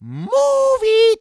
Mario yells "MOVE IT!" to throw the player off-guard. From Mario Golf: Toadstool Tour.